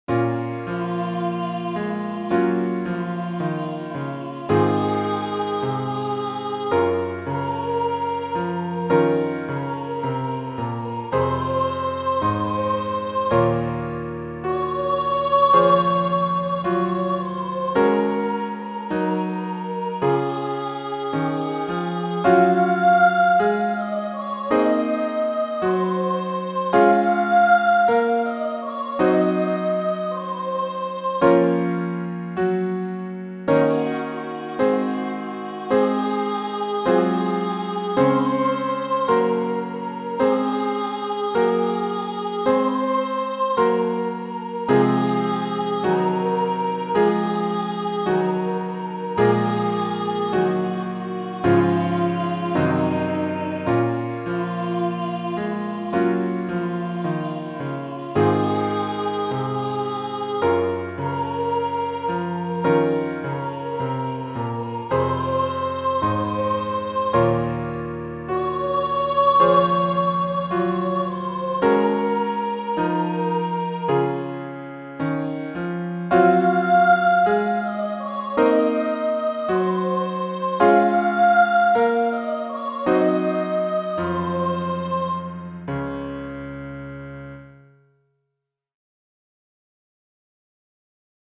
X. Aura, aura marina, - midi X studio - voce1